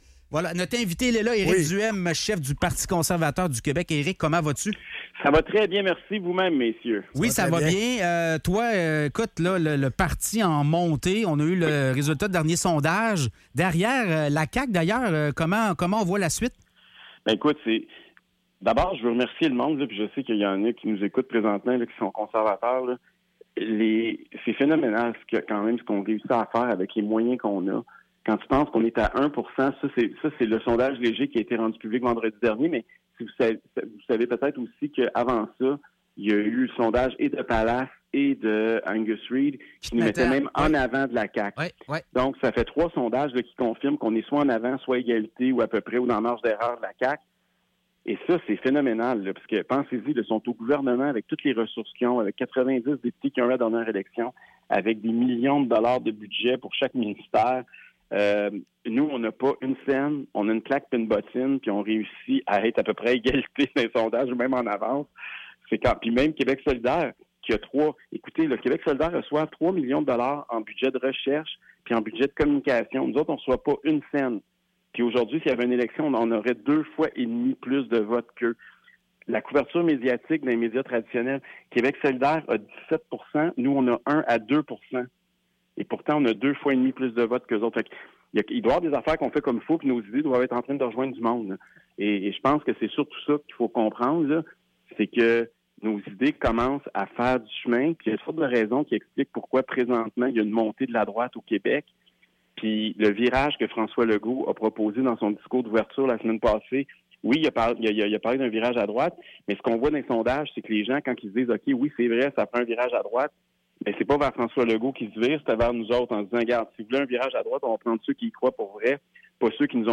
Entrevue avec Éric Duhaime du PCQ